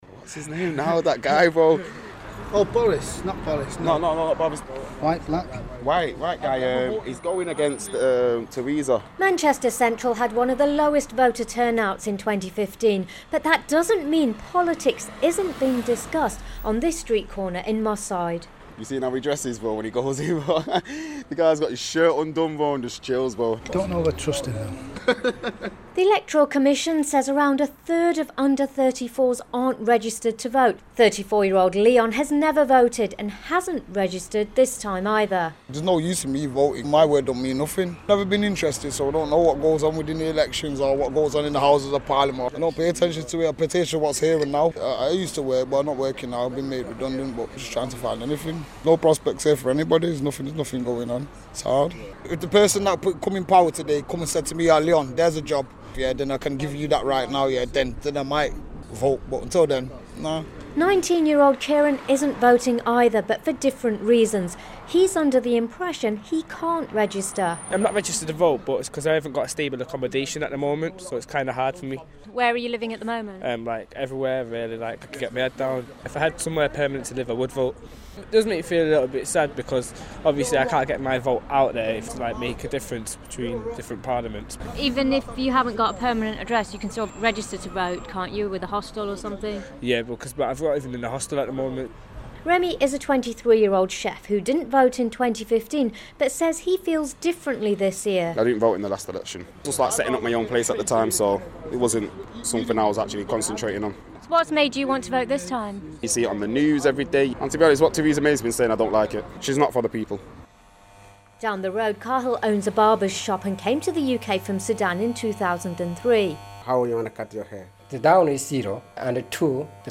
went to Moss Side in Manchester for BBC Radio 4 Today